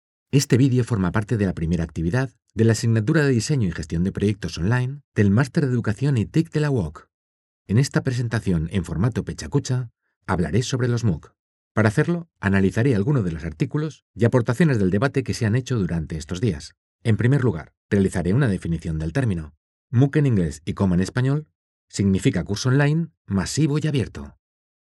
E-Learning
Voz neutral, emotiva, energética y divertida
Profesional Studio at home